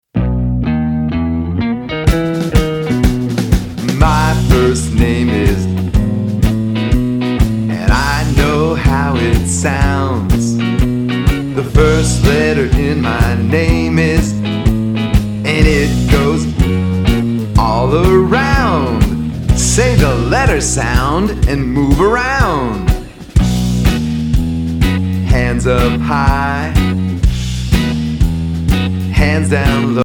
Listen the open version of this song.